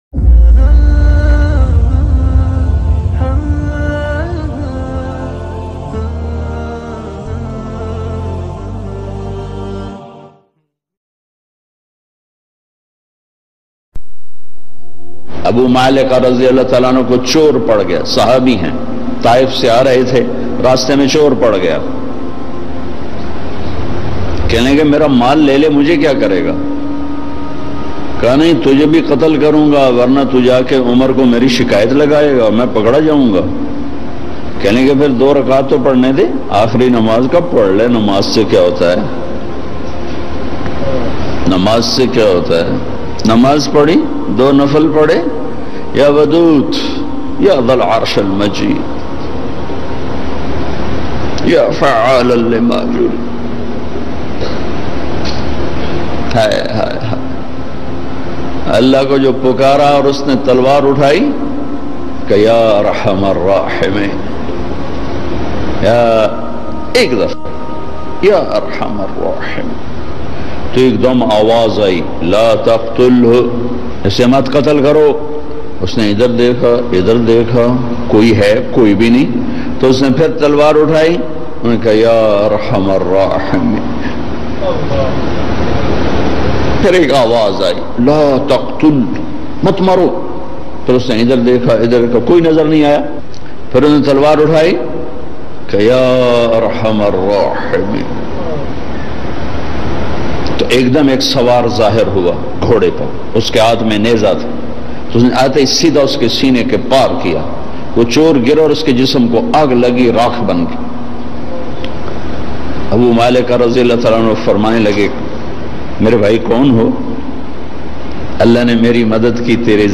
Ek Sahabi Ka Qissa Jiski Pukar Per Allah bayan mp3